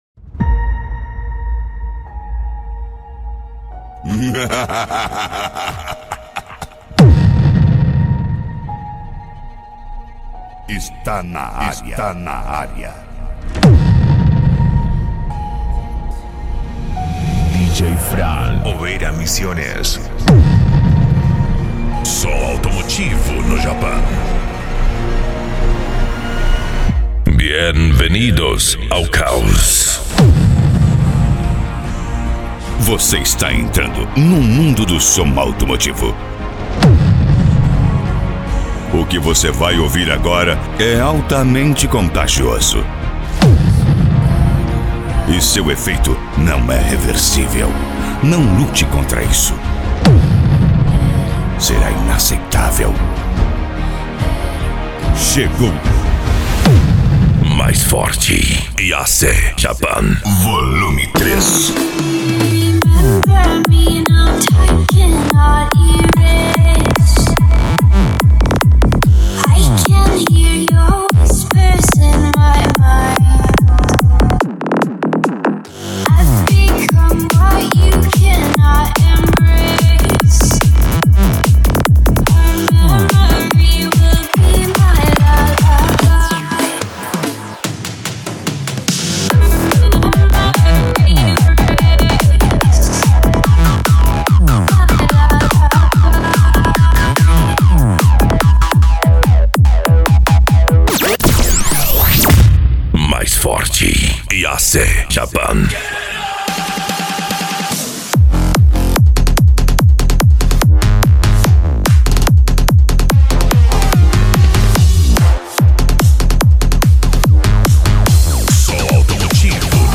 japan music